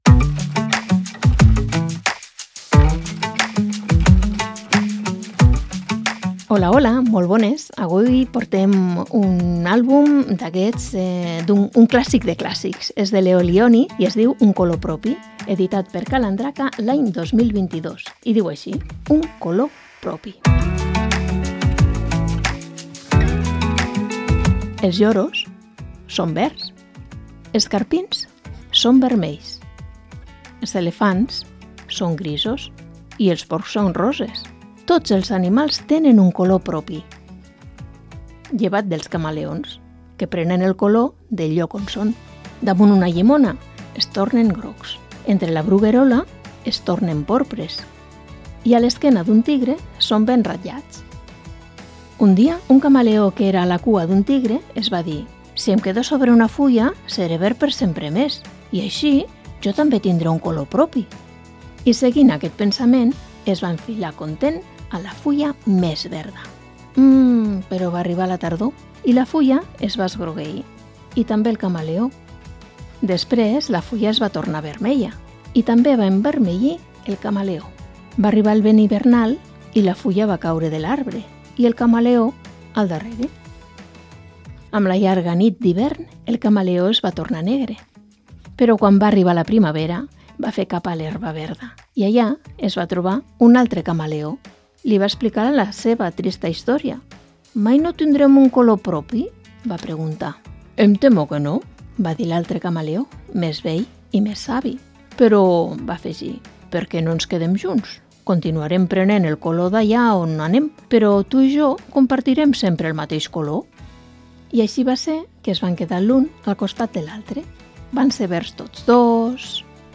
Programa de lectura de contes